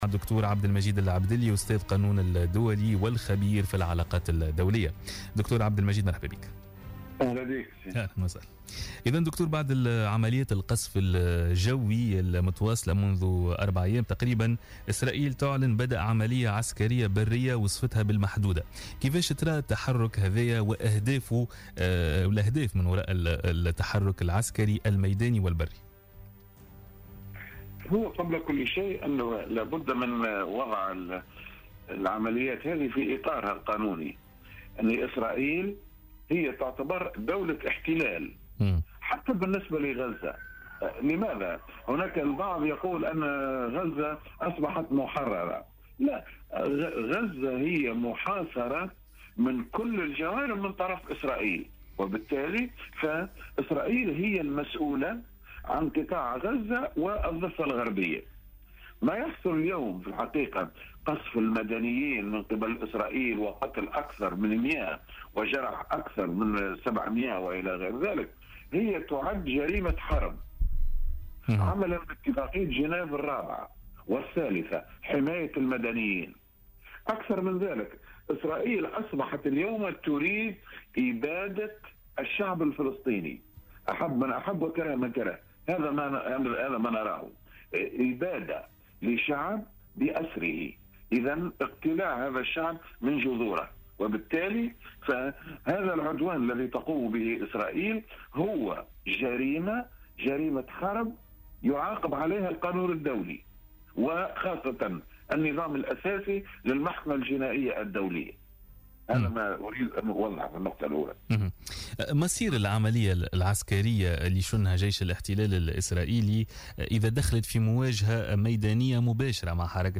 مداخلة على جوهرة "اف ام"